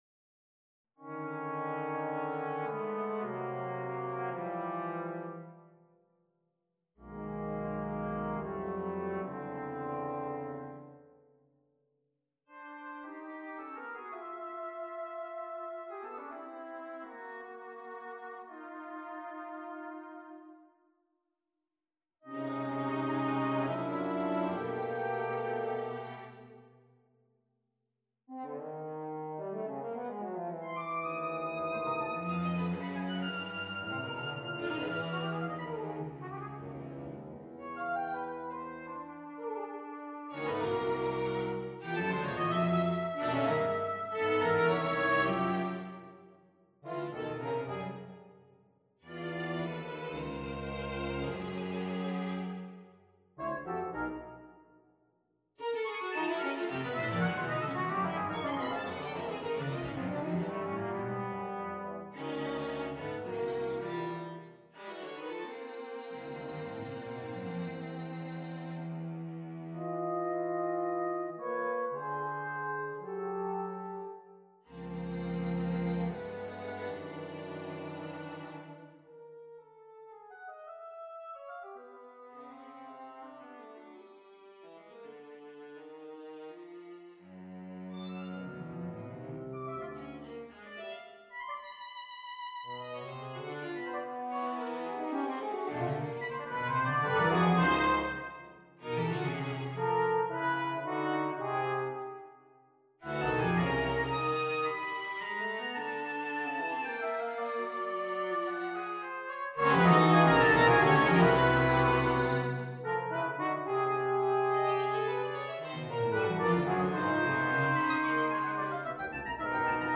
on a purpose-selected tone row 1. Allegretto con moto 2. Grave ma non troppo 3. Allegro energico - Allegro molto - Tempo Primo - Molto meno mosso - Meno mosso molto ancora Date Duration Download 11 September 2025 15'17" Realization (.MP3) Score (.PDF) 13.9 MB 1.0 MB